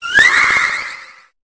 Cri de Cupcanaille dans Pokémon Épée et Bouclier.